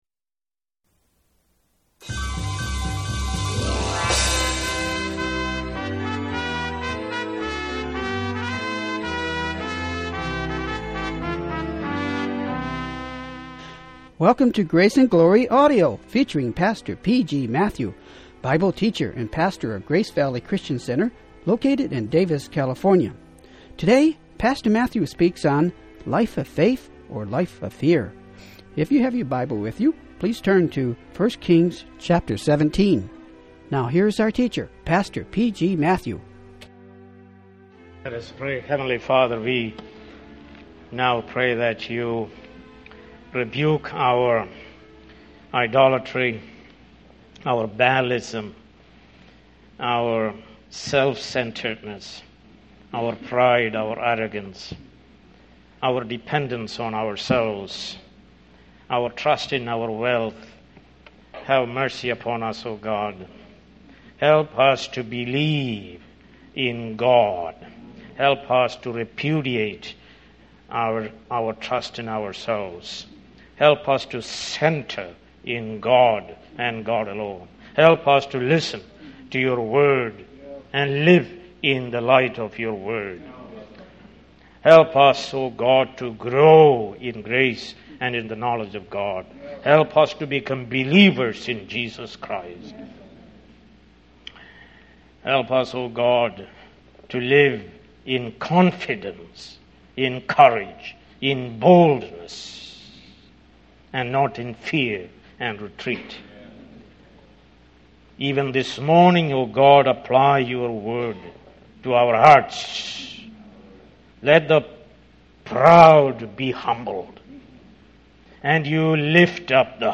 Sermons | Grace Valley Christian Center